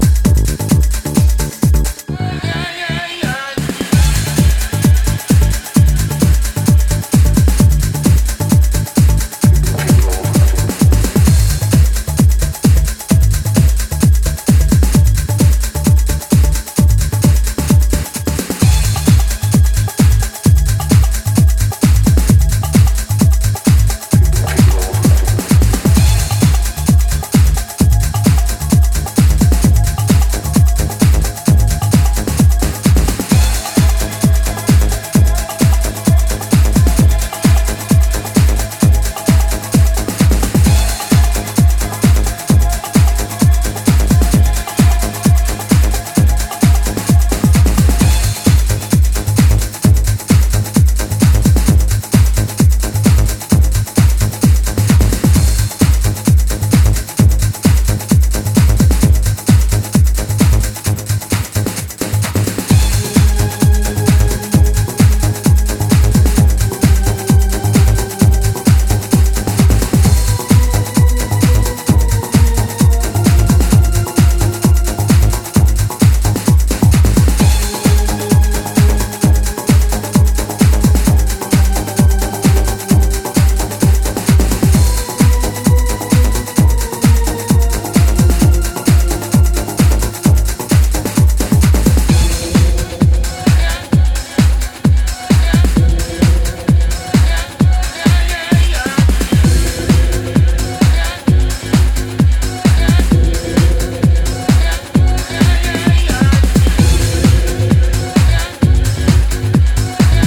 quirky bass lines and moody synth work